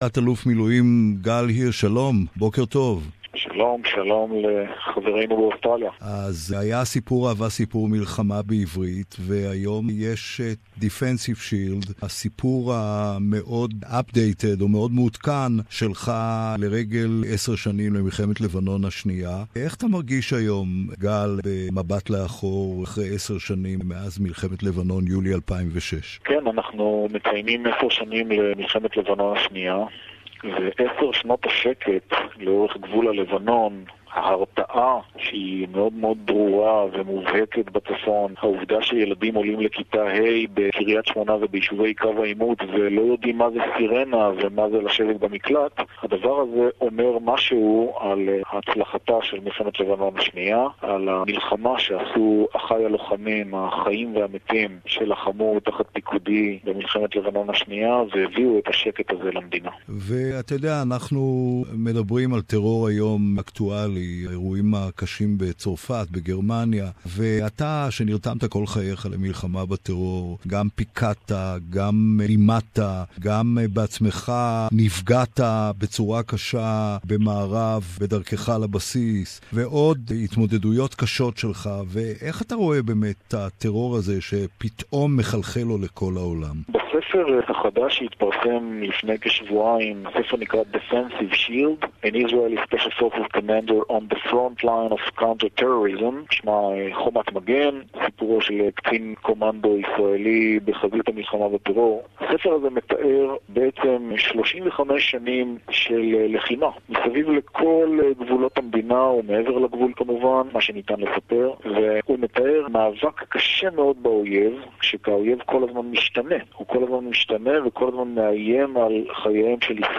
Brigadier General (Ret.) Gal Hirsch Hebrew Interview - Part 1